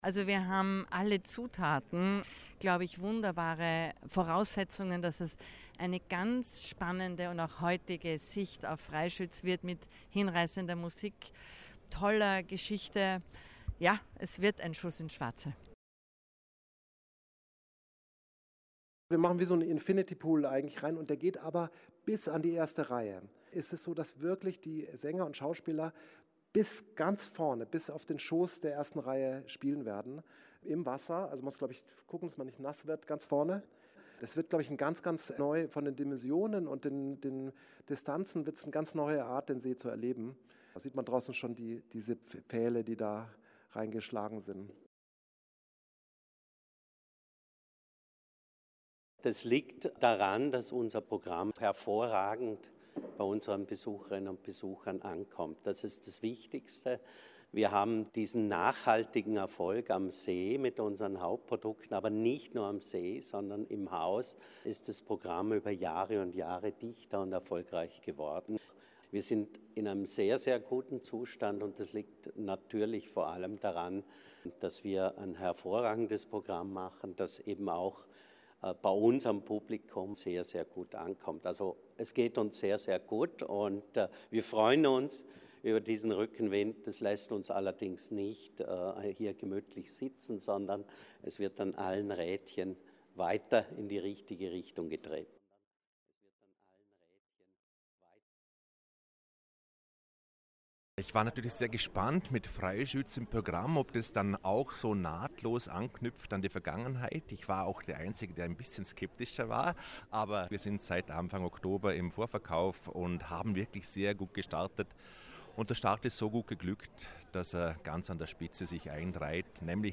Pressekonferenz Festspielprogramm 2024 Feature